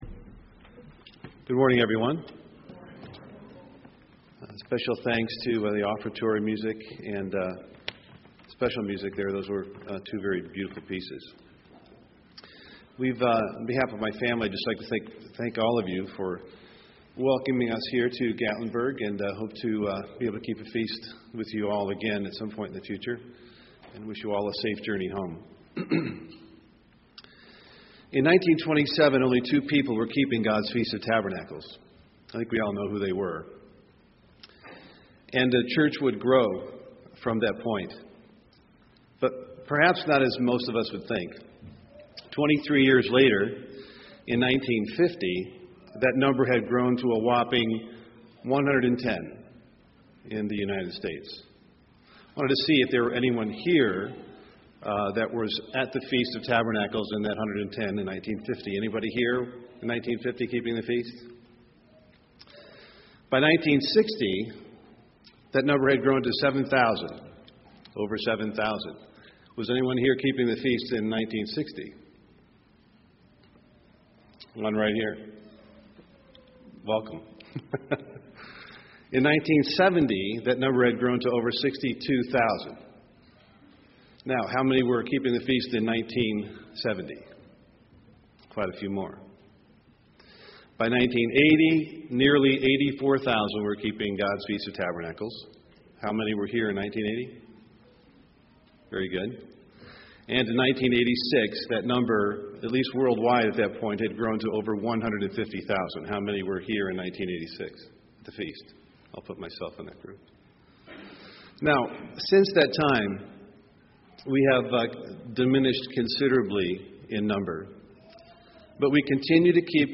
This sermon was given at the Gatlinburg, Tennessee 2013 Feast site.